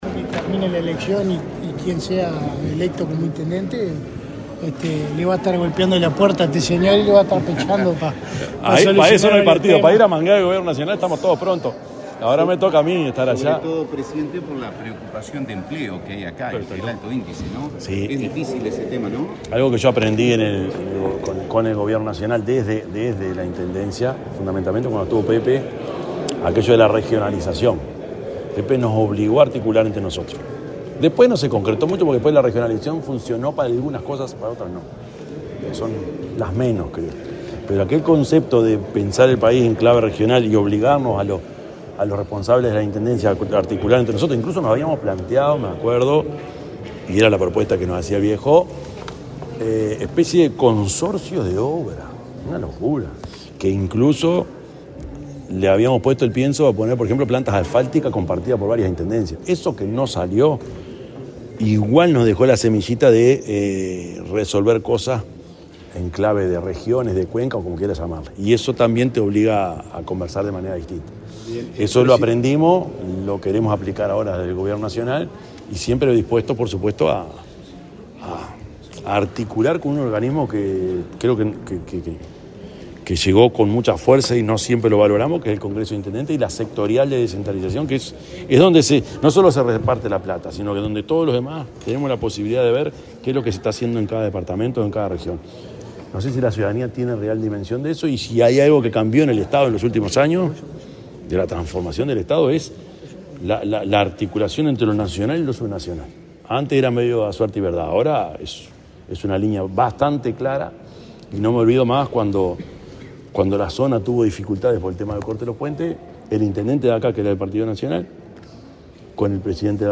Declaraciones del presidente de la República, Yamandú Orsi
El presidente de la República, profesor Yamandú Orsi, dialogó con la prensa en Río Negro, donde participó del lanzamiento del Plan de Salud Mental del